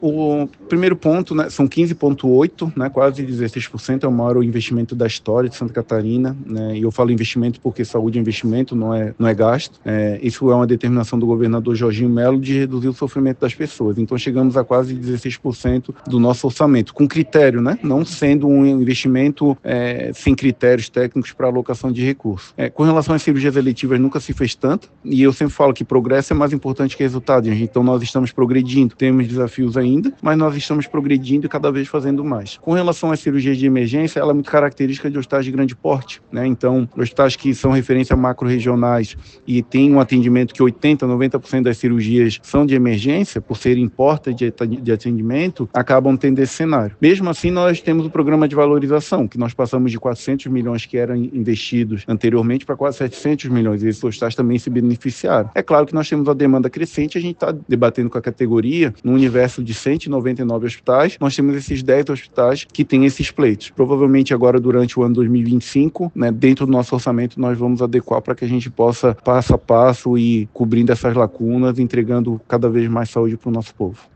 Nesta terça-feira, 25, o secretário de estado da Saúde, Diogo Demarchi Silva, compareceu à Comissão de Saúde da Assembleia Legislativa de Santa Catarina (Alesc) para apresentar o relatório das ações da pasta nos últimos quatro meses de 2024.